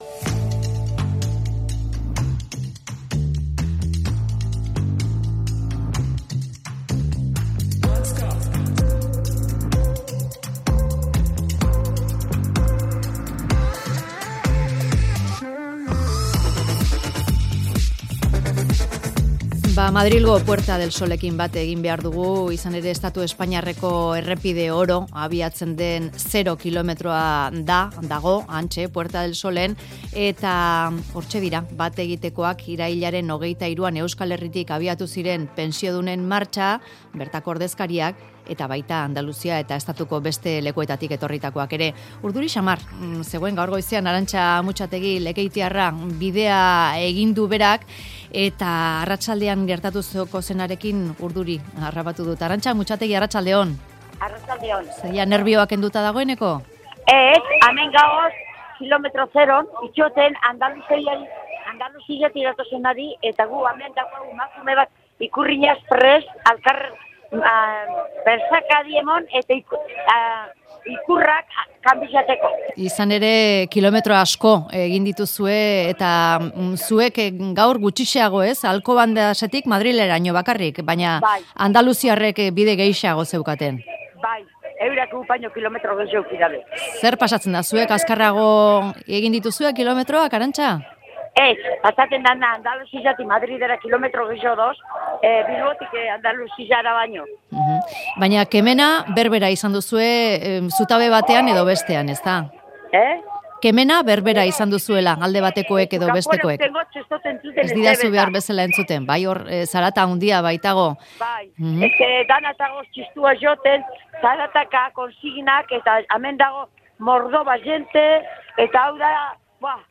Hunkituta mintzatu zaizkigu pentsiodunak Madrilera iritsi berritan
Sol plazatik bertatik eman digu lekukotza.